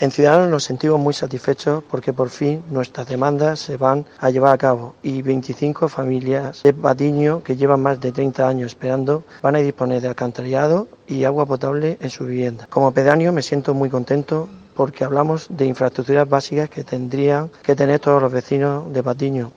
audio_antonijimenez_pedaneo_Patiño.mp3